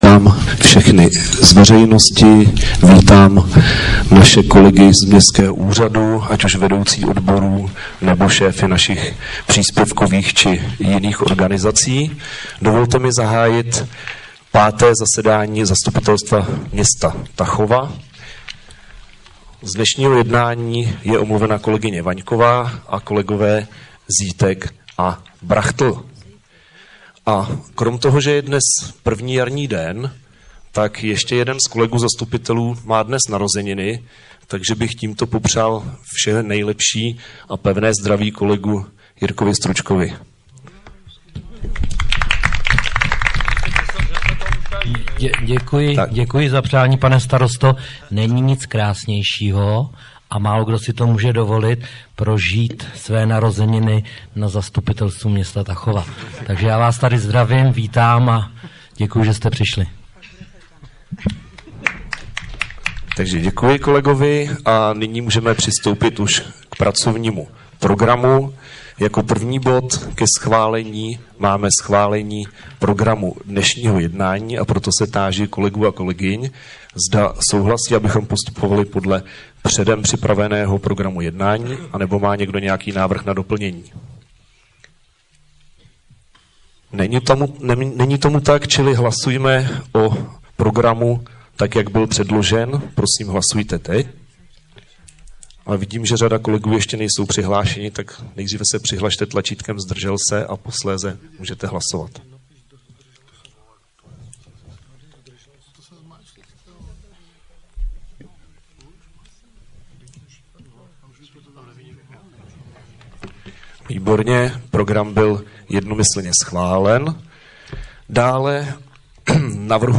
5. zasedání zastupitelstva města 20. března 2023
Audiozáznam zasedání.mp3